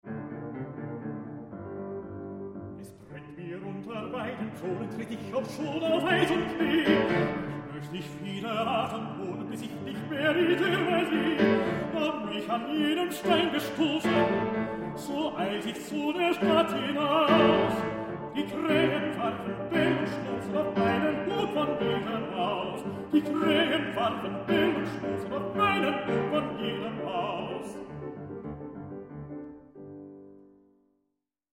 Tenor
Piano
This studio recording